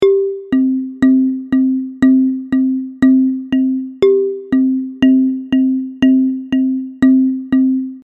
Achtelnoten zählt man häufig als „1-und-2-und-3-und-4-und“. Im zweiten Audiobeispiel bleibt das Tempo gleich – nur die Unterteilung wird feiner.
Audiobeispiel_Viervierteltakt_mit_Achteln.mp3